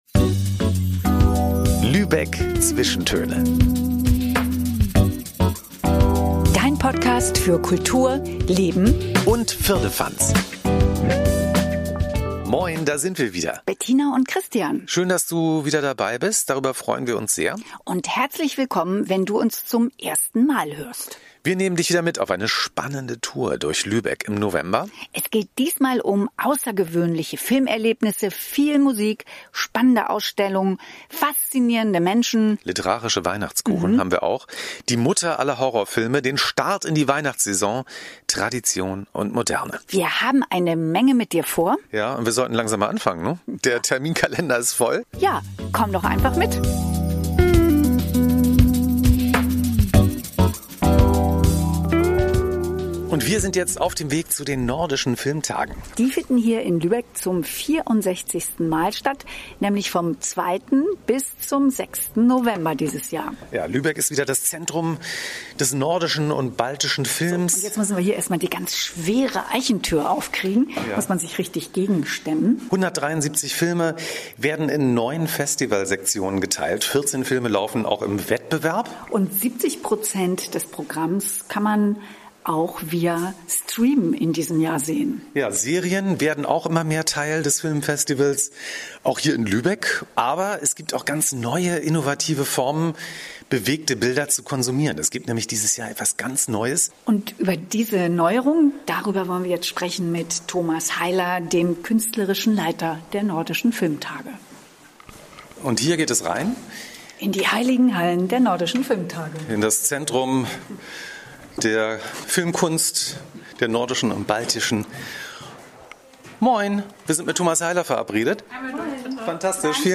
Die Nordischen Filmtage kannst du jetzt im 360 ° Grad Blickwinkel erleben. Du hörst ein exklusives Mini-Konzert in der kultigen Musik-Kneipe „Café Tonfink“. Du erfährst alles über Luxus, Lotterleben, Lifestyle und Tee im St. Annen Museum.